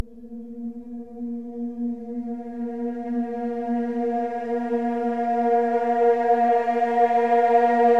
黑色唱诗班的咆哮声
描述：像德雷的合唱团;)
标签： 90 bpm Hip Hop Loops Choir Loops 1.35 MB wav Key : F
声道立体声